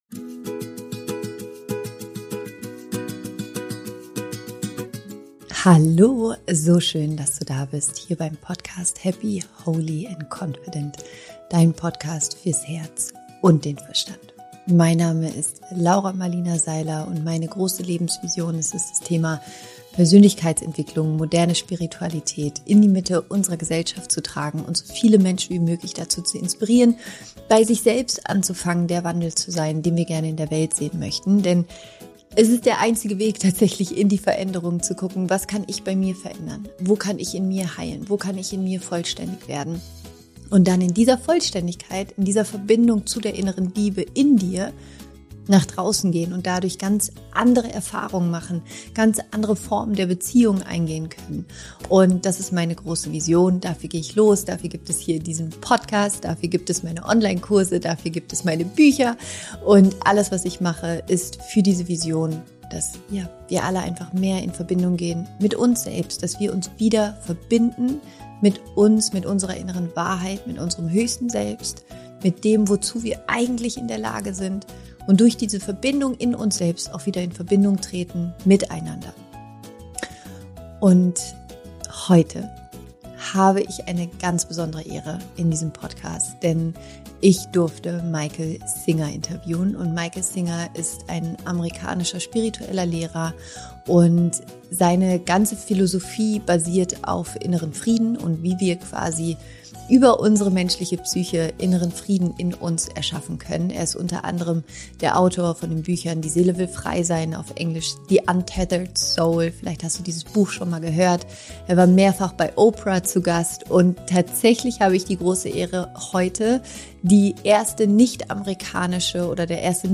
Im neuen Podcastinterview habe ich den Autor, Philosophen und spirituellen Lehrer Michael A. Singer zu Gast und wenn ich dir eins versprechen kann, dann, dass dieses Gespräch tief geht!
Im Interview sprechen Michael und ich über Spiritualität, Realität, die menschliche Psyche und er erklärt, wie du ein unbeschwertes, glückliches und freies Leben führen kannst.